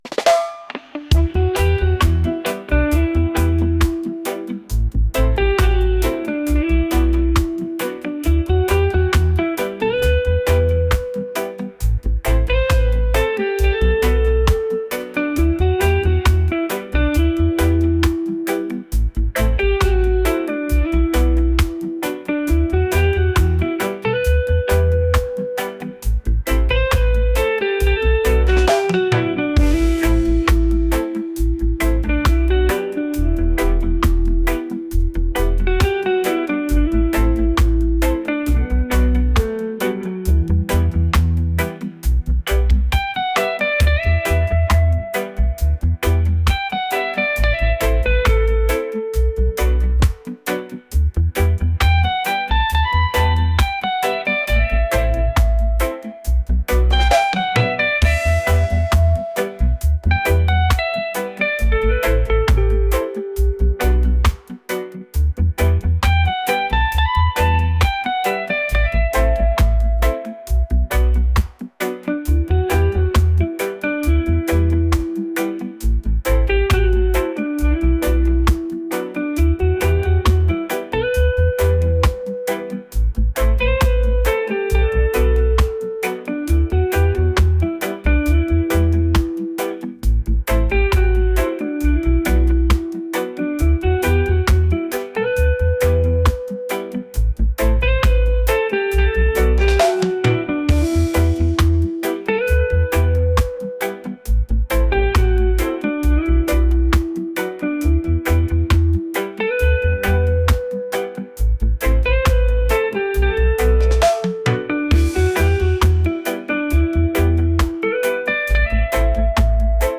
soulful | reggae